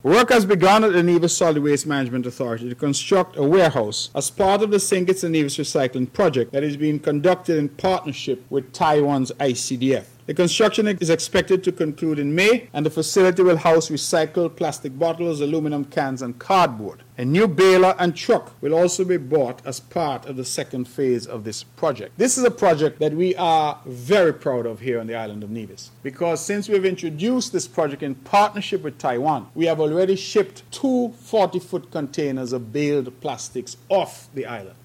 Developments continue with the Sustainable Island State Agenda (SISA) focusing on sustainable development goal of responsible consumption and production. This is Premier, the Hon. Mark Brantley: